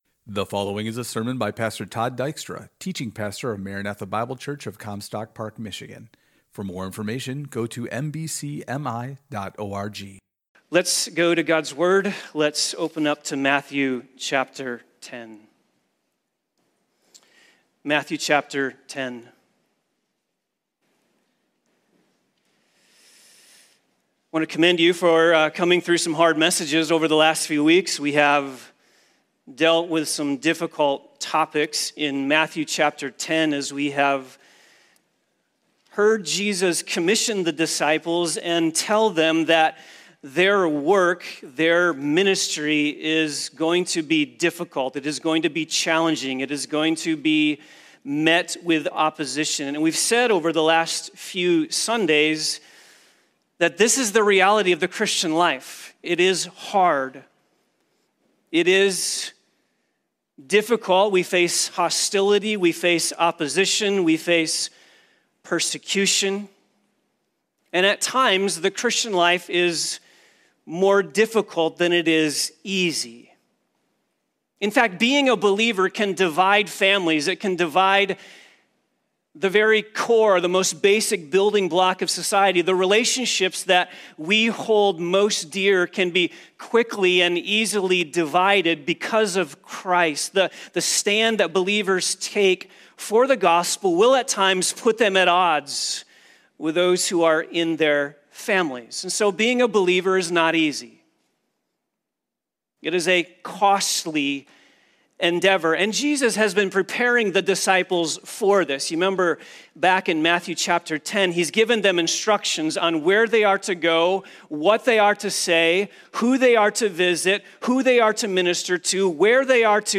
THERE IS A 70 SECOND BLANK IN THE RECORDING AT AROUND 22 MINUTES. IT BEGINS AGAIN JUST AFTER 23 MINUTES